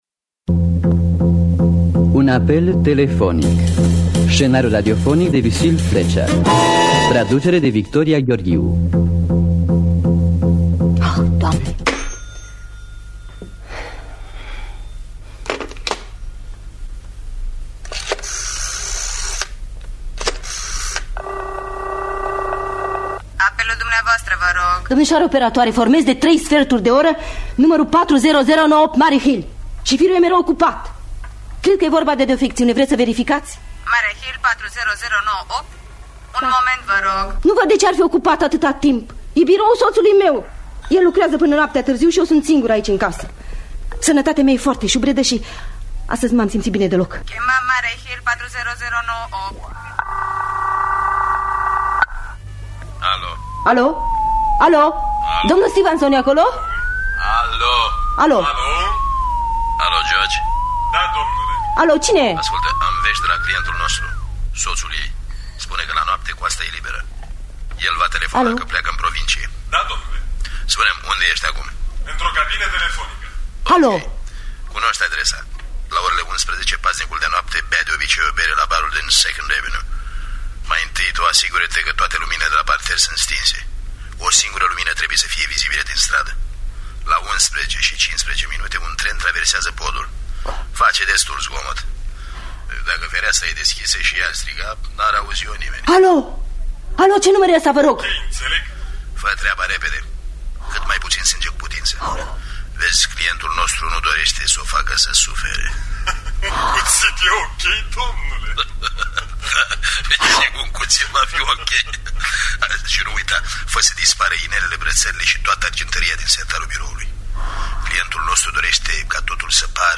Un apel telefonic de Lucille Fletcher – Teatru Radiofonic Online